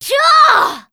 cleric_f_voc_attack02_f.wav